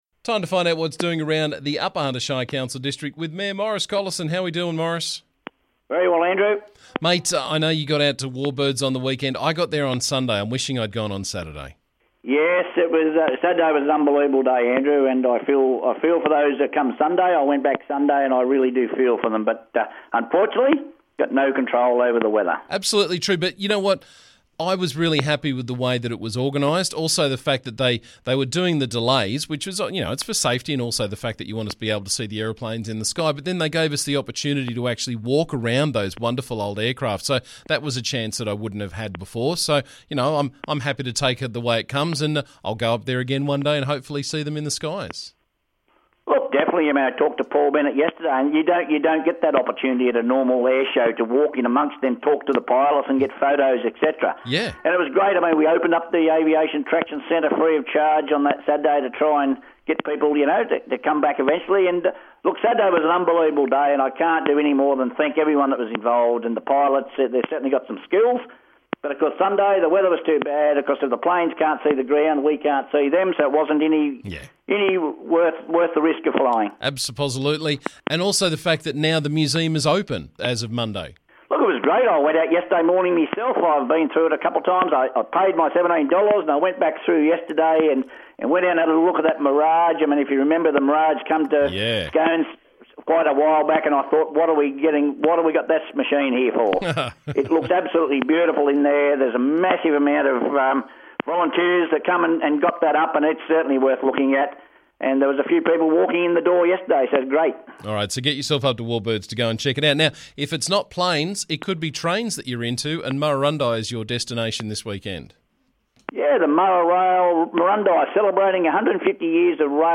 UHSC Mayor Maurice Collison was on the show this morning to keep us up to date with what's doing around the district.